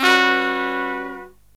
LONG HIT01-L.wav